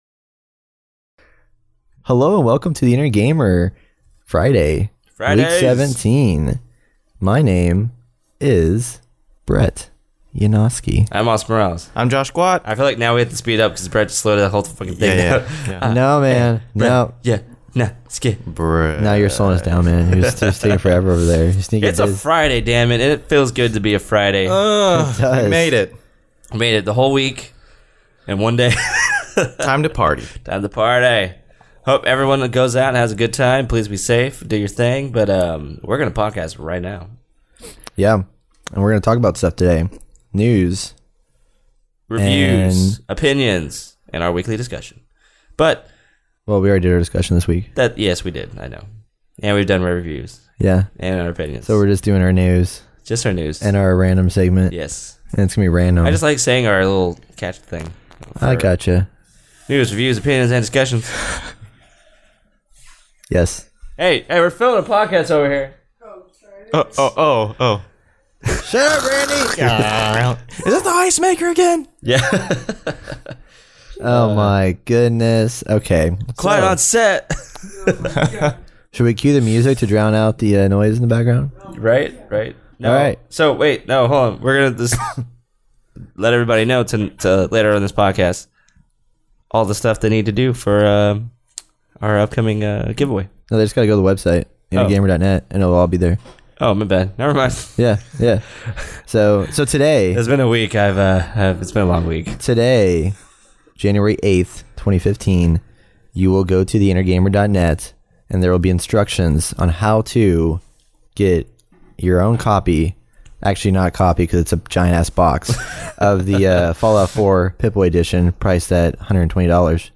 We also talk a load about Virtual Reality from CES 2016, some majorly awesome PC's and our random segment about random things. The Inner Gamer is a podcast built for the casual gamer.